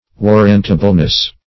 warrantableness - definition of warrantableness - synonyms, pronunciation, spelling from Free Dictionary
[1913 Webster] -- War"rant*a*ble*ness, n. --